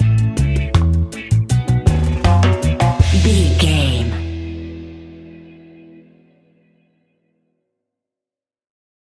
Classic reggae music with that skank bounce reggae feeling.
Ionian/Major
reggae instrumentals
laid back
chilled
off beat
drums
skank guitar
hammond organ
percussion
horns